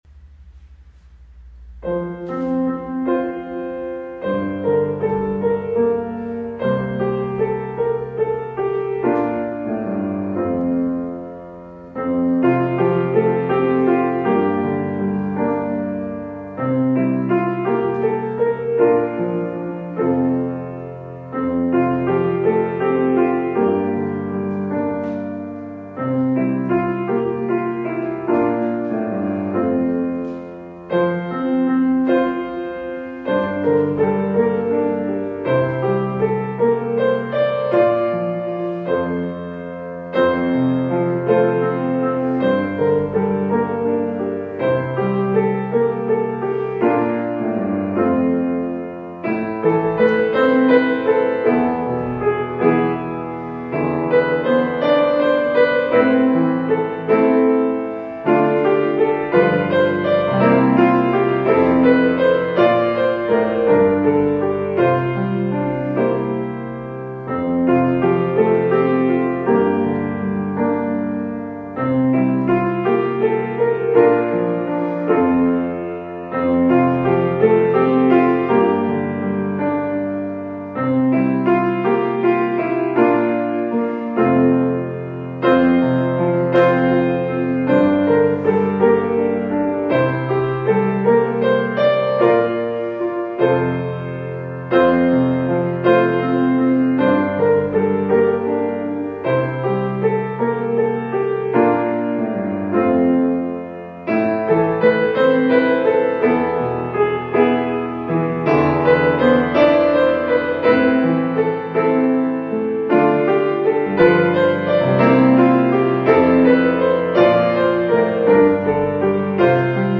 nach Psalm 103, Melodie: Norbert Kissel